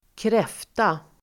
Uttal: [²kr'ef:ta]